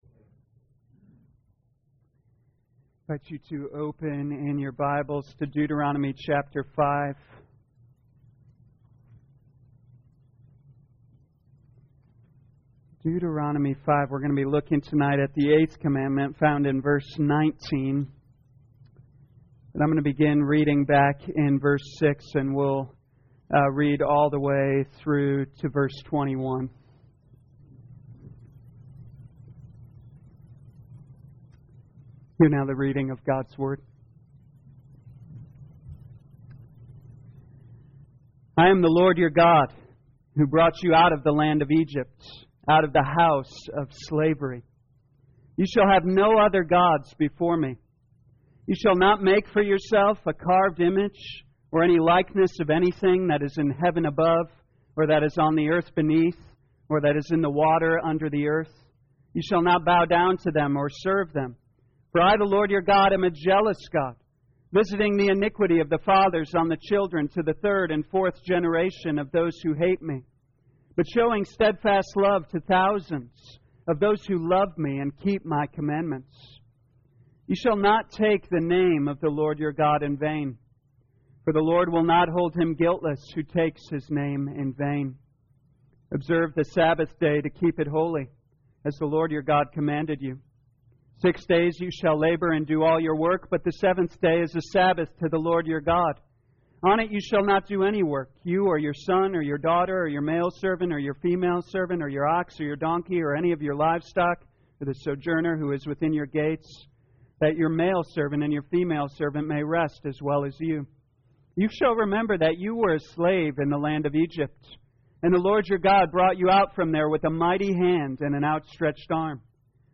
2022 Deuteronomy The Law Evening Service Download